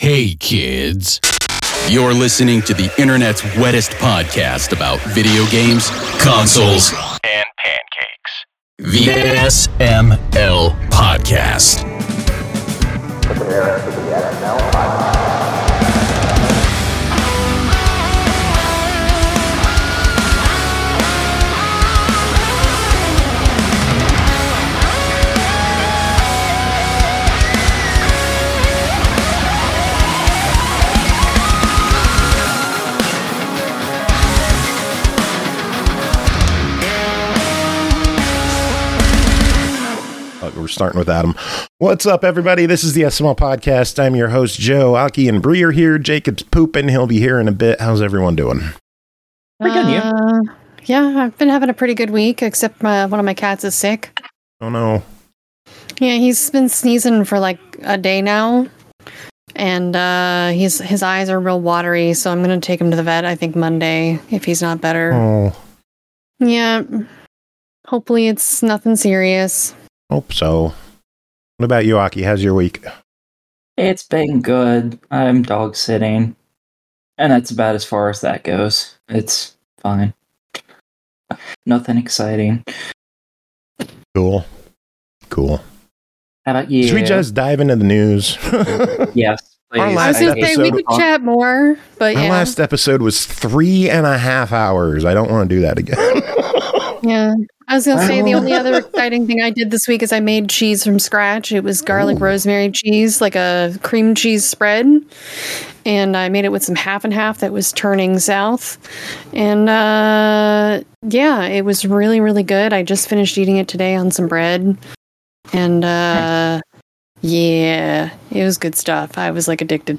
Everybody is tired and not feeling hot, so we’re just gonna rush through the show, sound good? Good.